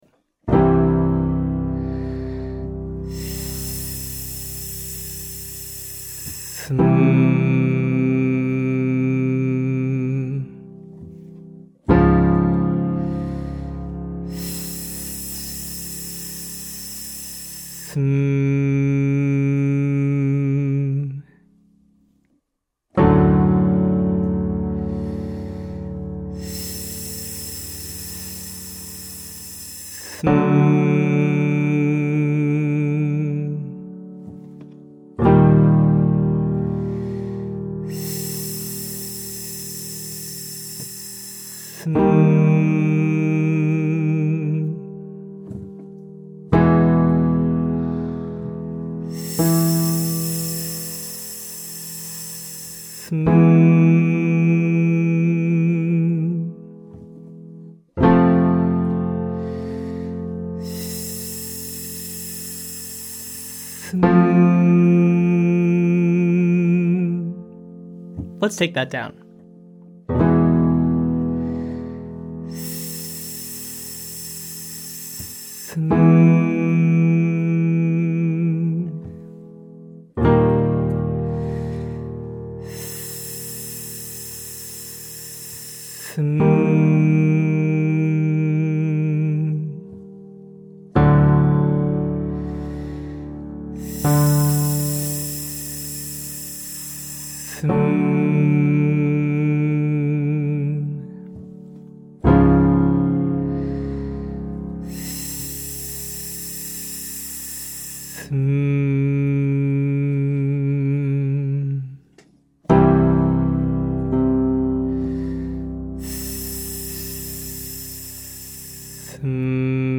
Exercise: Hiss to hum (4 counts each)
Take your favorite semi-closed position, like singing through a straw, a lip trill, an NG or a hum, and slide.
Exercise: Narrow vowel (OO or EE) octave slide 181